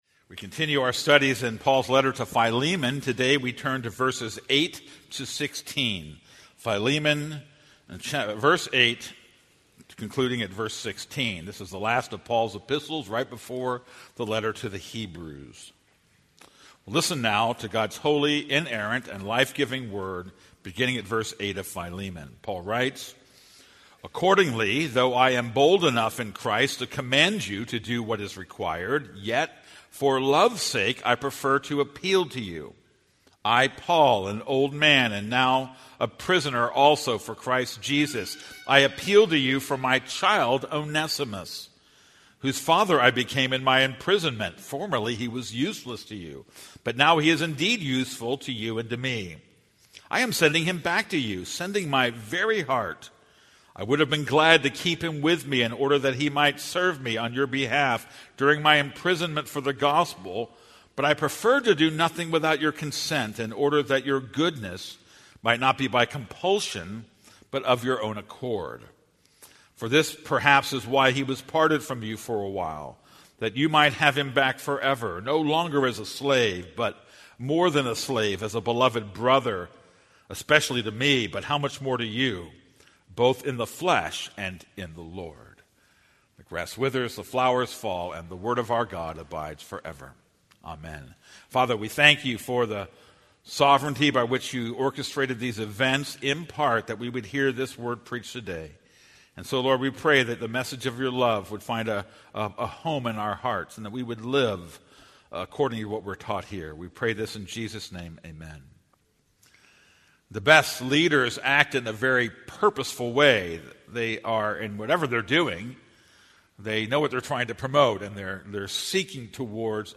This is a sermon on Philemon 1:8-16.